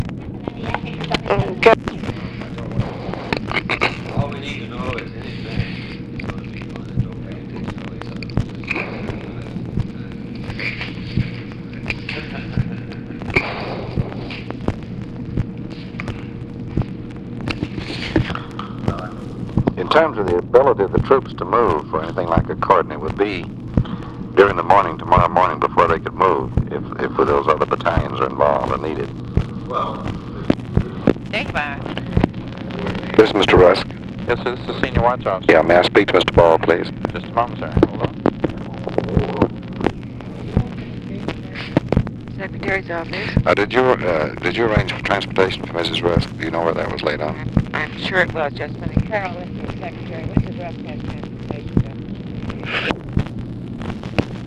Conversation with OFFICE SECRETARY and OFFICE CONVERSATION
Secret White House Tapes | Lyndon B. Johnson Presidency